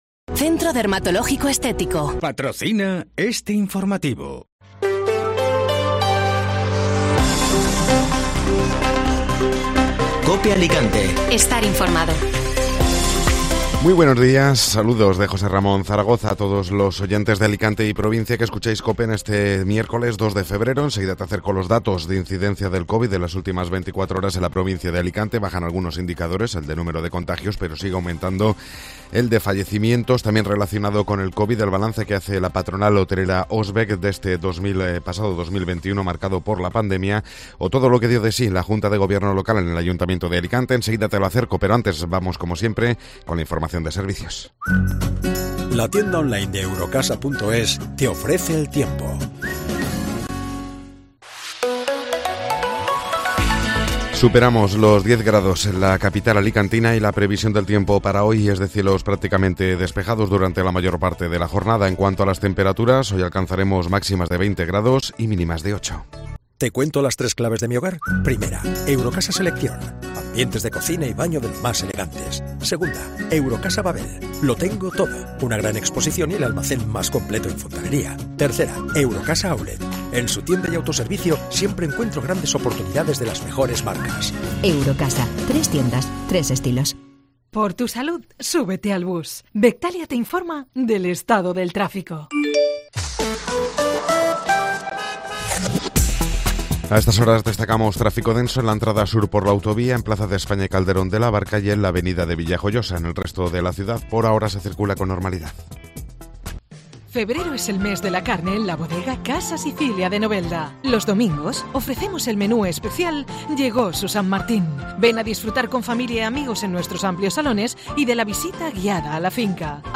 Informativo Matinal (Miércoles 2 de Febrero)